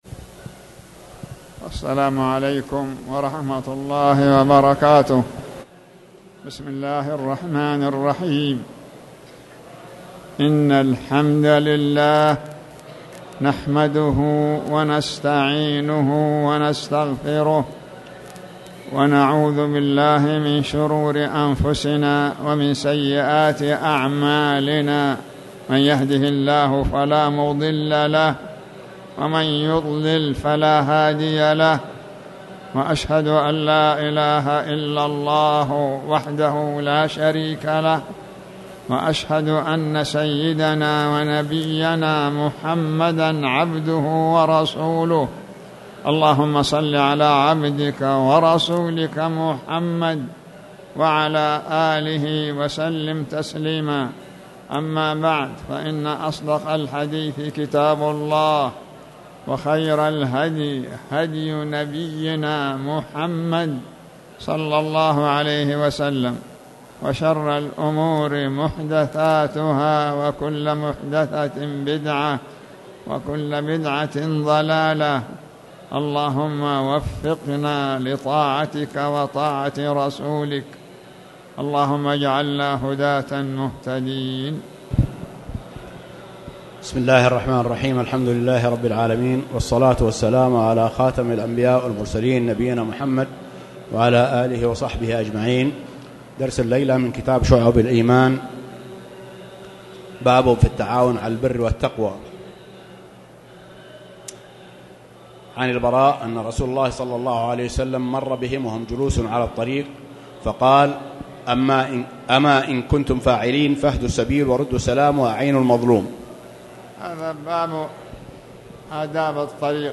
تاريخ النشر ٢٢ شوال ١٤٣٨ هـ المكان: المسجد الحرام الشيخ